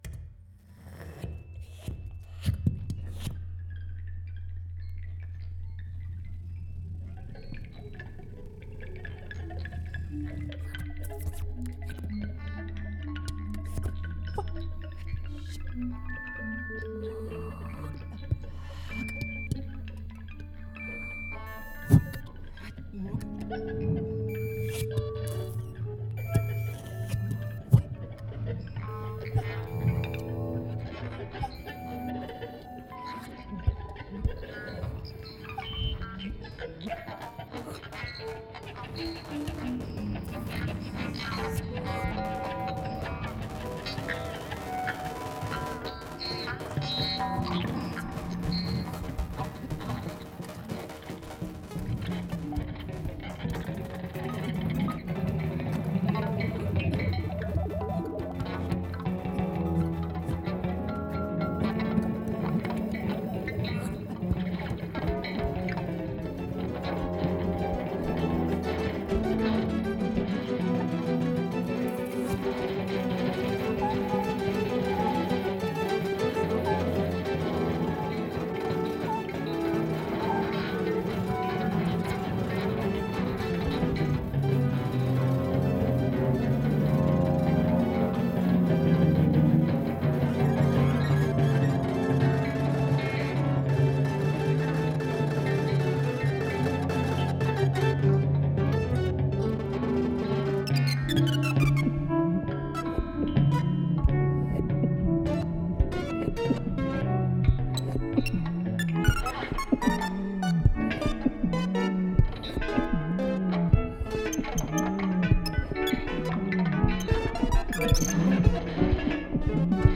Annual 3-night celebration of the late guitarist, bringing together rotating combinations of master improvisers—selected by 3 guest curators.
Each evening will present a rotating kaleidoscope of musicians, who will then divide into smaller improvisational units—each concert promising a unique experience.